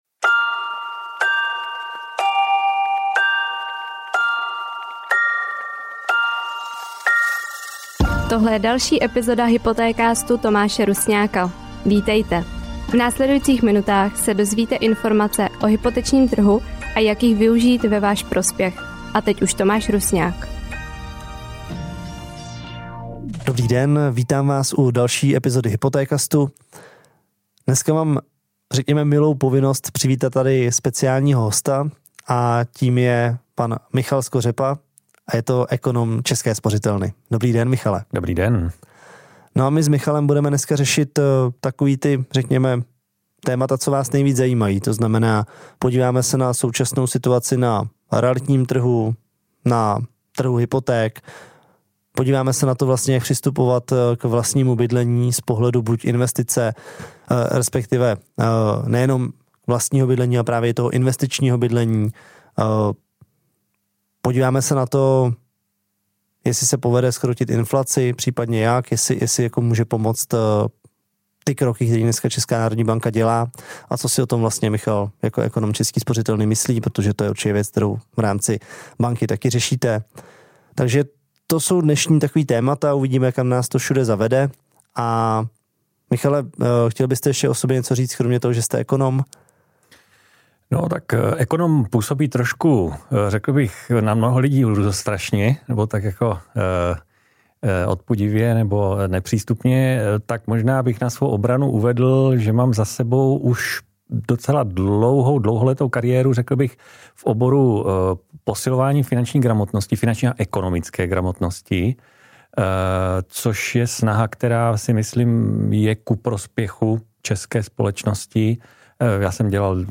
Rozhovor byl natočen před vypuknutím války na Ukrajině.